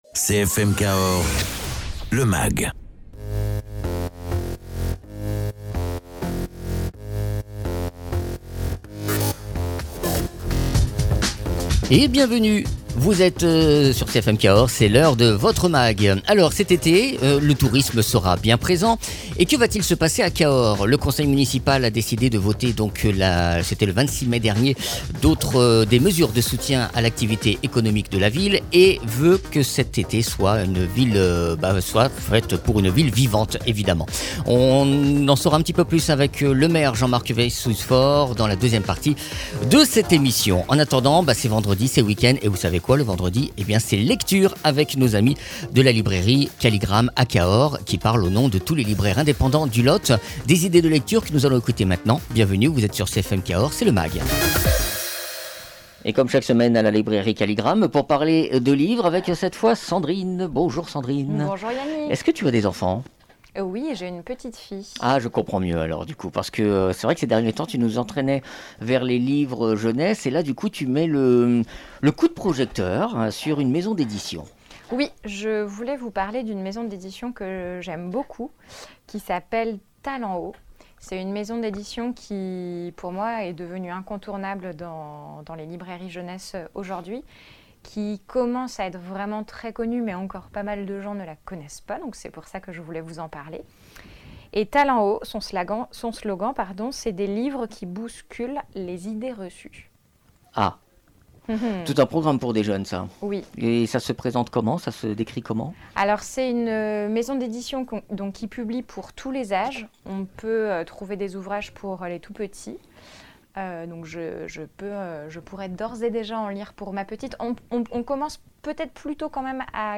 Jean Marc Vayssouze Faure, Maire de Cahors